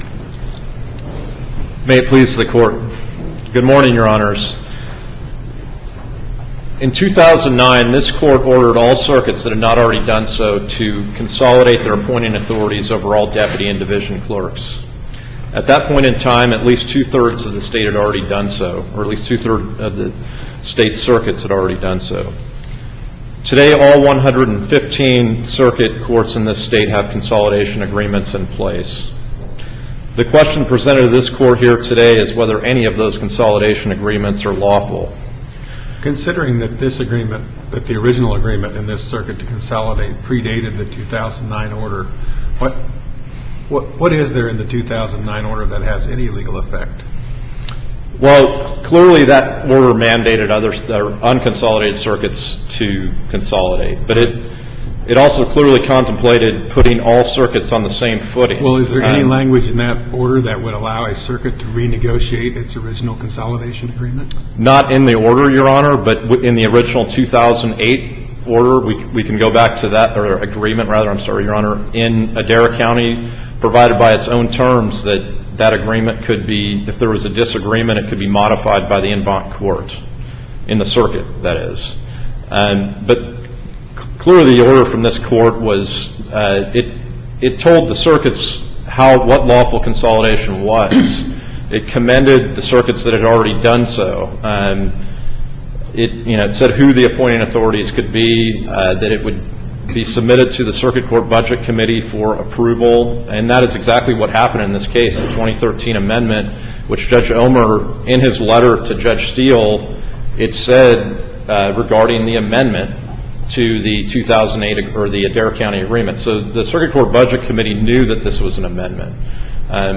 MP3 audio of argument in SC96280